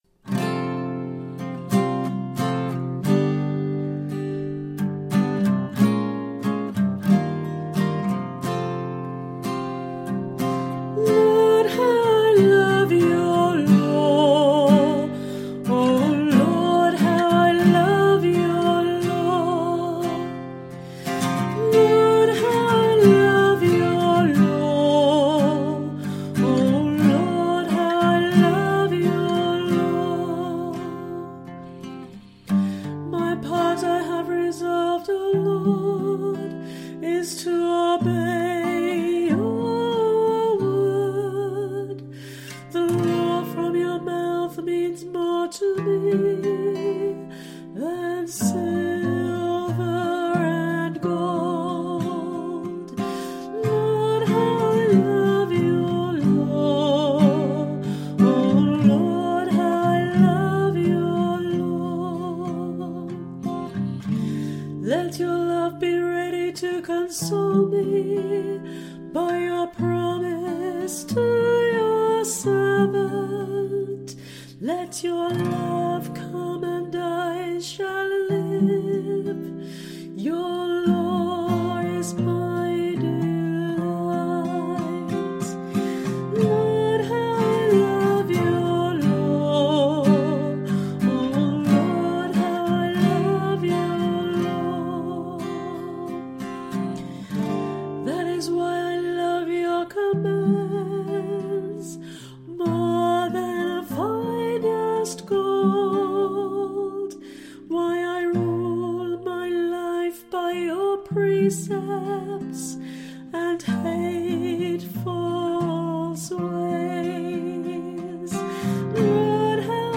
Responsorial Psalm for the 17th Sunday in Ordinary Time